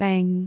speech
syllable
pronunciation
beng3.wav